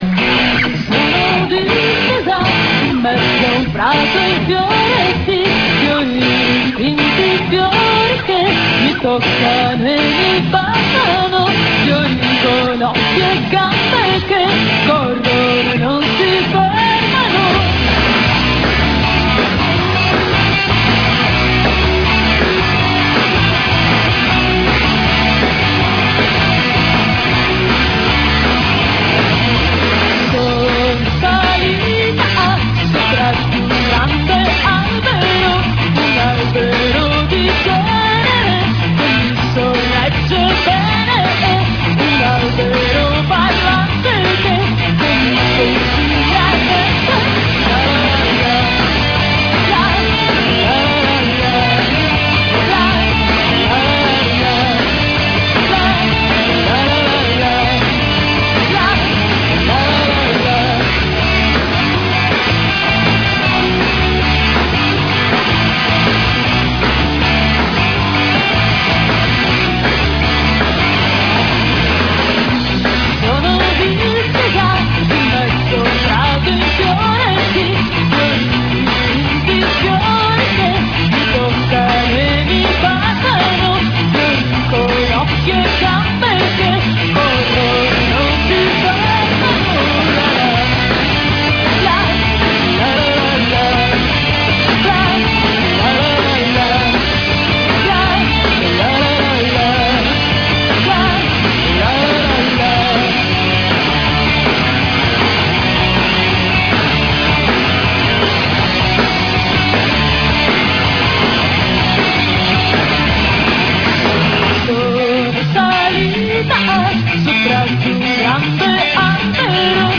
Live agli Studi Rai